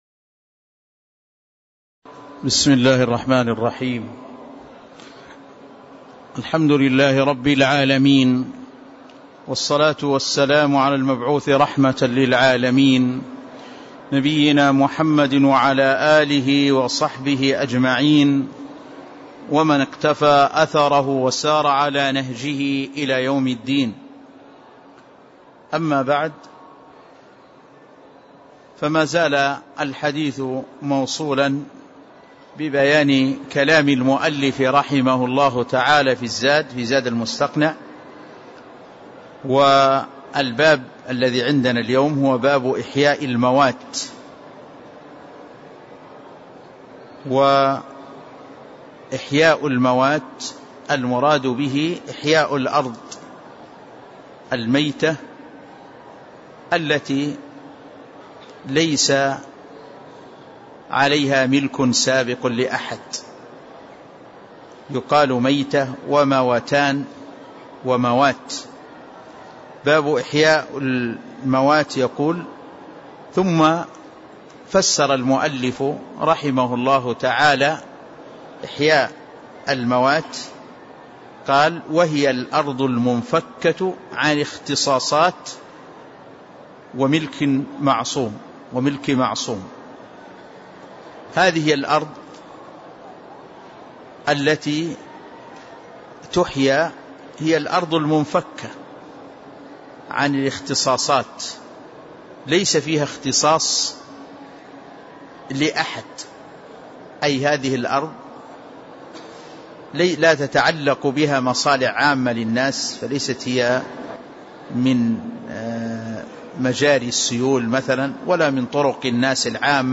تاريخ النشر ٢٤ صفر ١٤٣٧ هـ المكان: المسجد النبوي الشيخ